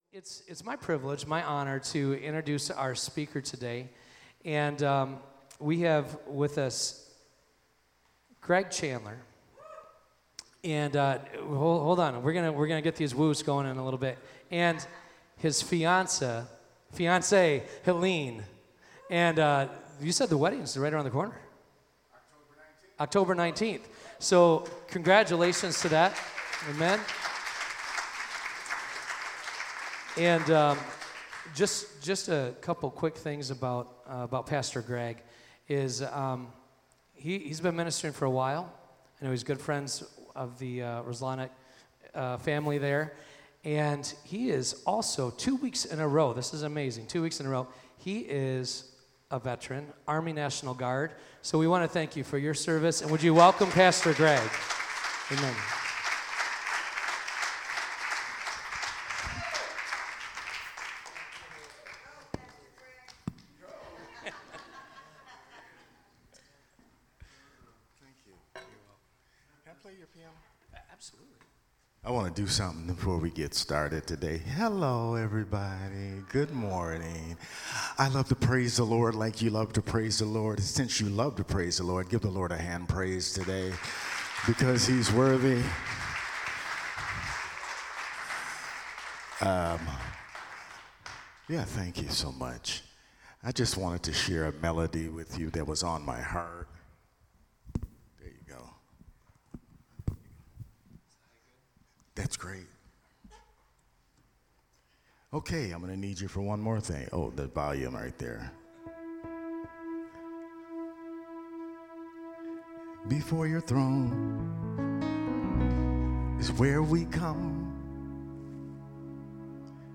Sermon-8-11-24.mp3